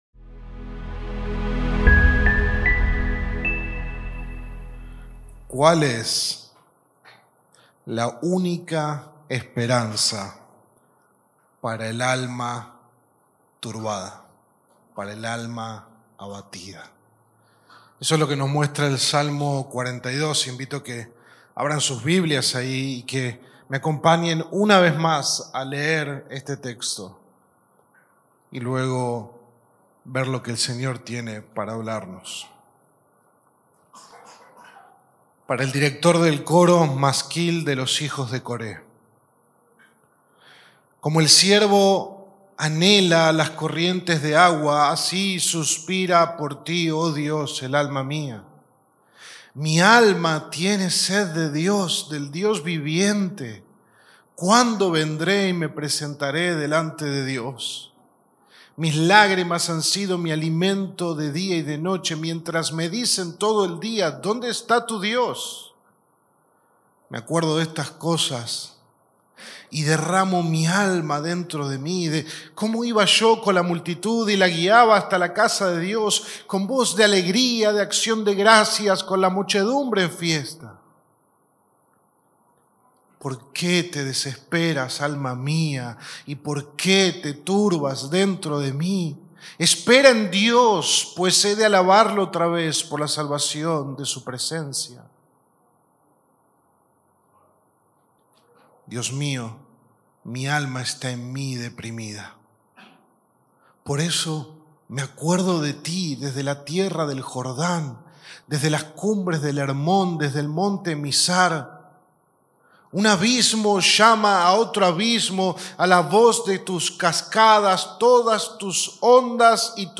Sermón 5 de 8 en Delante de Dios